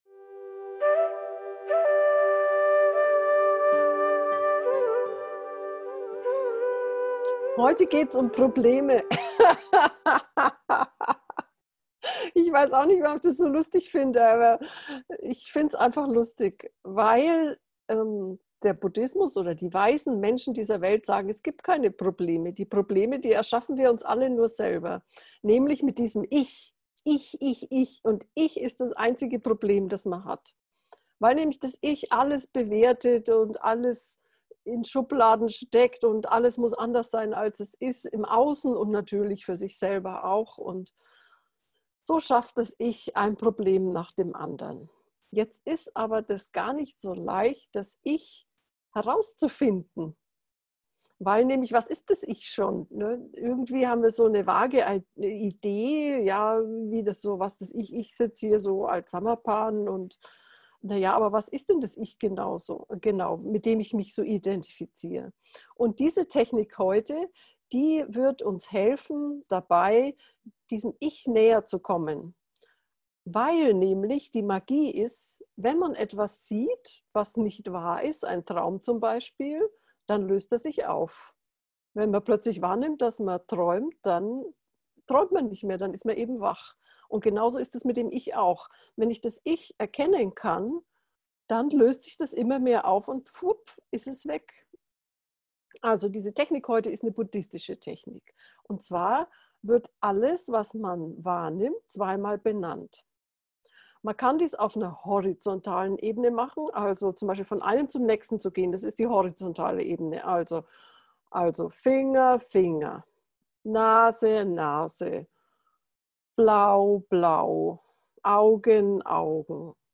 Geführte Meditationen Folge 38: Ich – das Problem??!